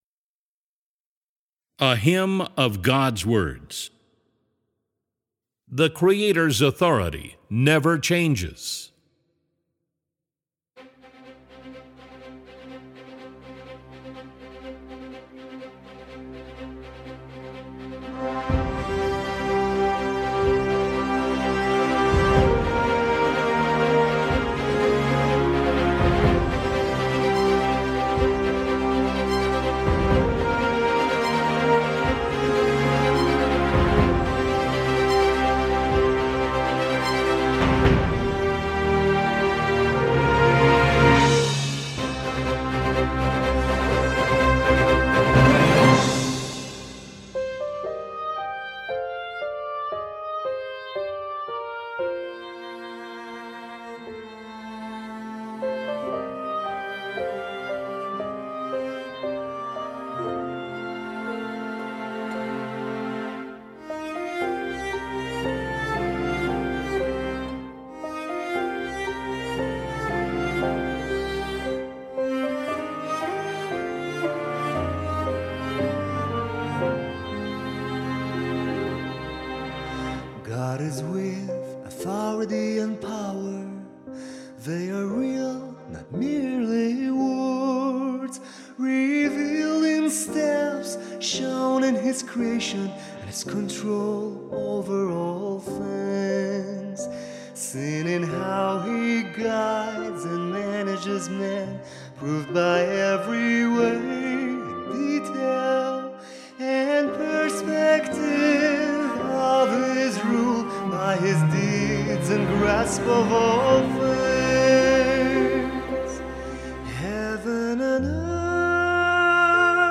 Categories: Hymns of God's Words